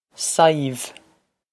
Pronunciation for how to say
Sive
This is an approximate phonetic pronunciation of the phrase.